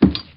PixelPerfectionCE/assets/minecraft/sounds/mob/horse/wood2.ogg at mc116